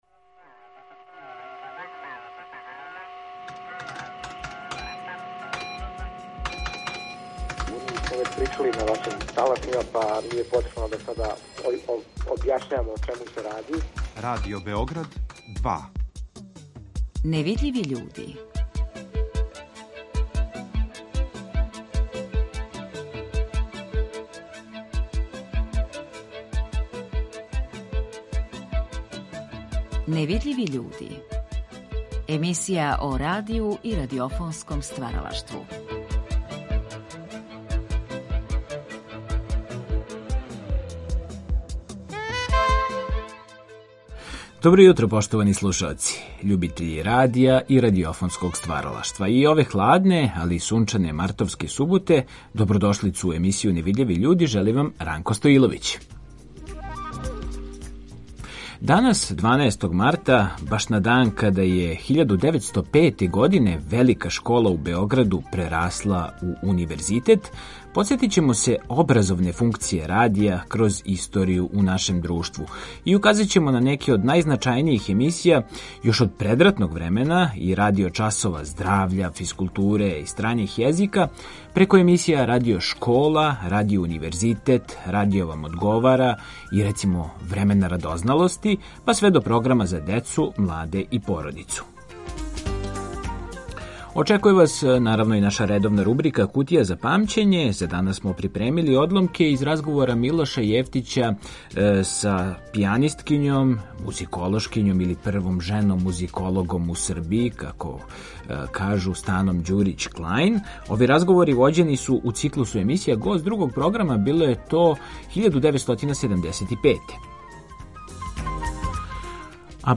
Ови разговори вођени су у циклусу емисија „Гост Другог програма".